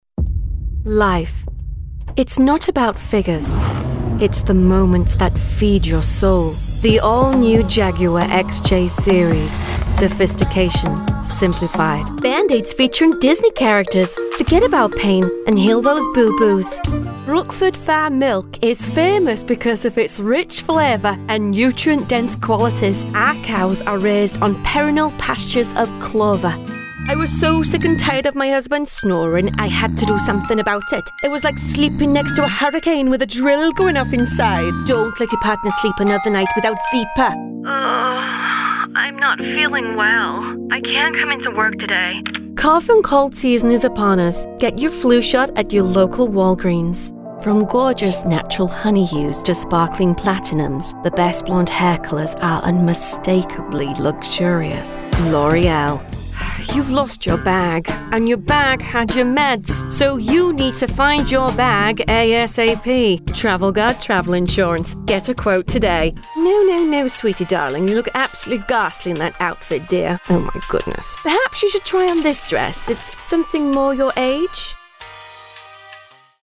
• Rode NT2-A condenser microphone
• Scarlett Focusrite